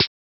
SNARE - Quik Quik.wav